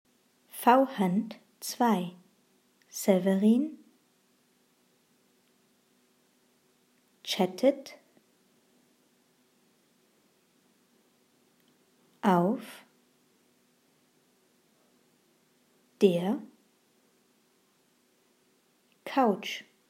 Satz 1 Langsam